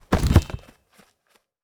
Foley Sports / Skateboard / Bail B.wav